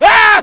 scream14.ogg